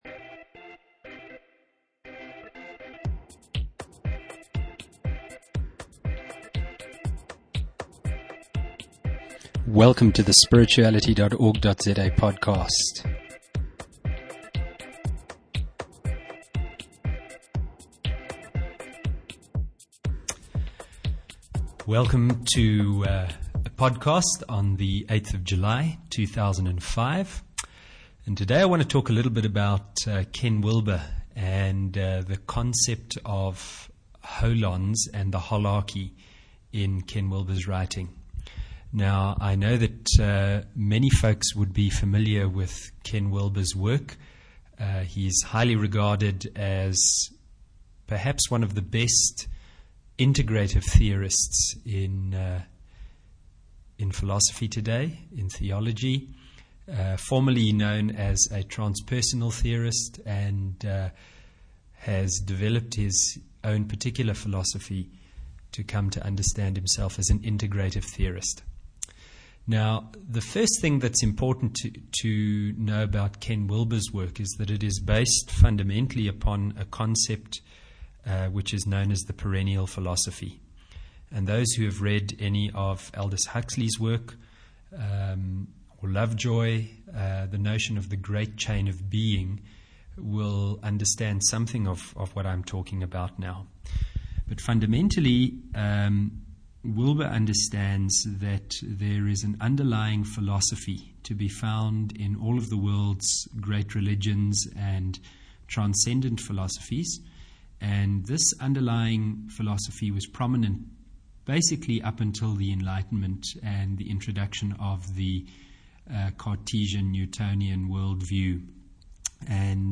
Please do excuse the quality - I was still figuring out my equipment, and certainly didn't have much confidence with recording!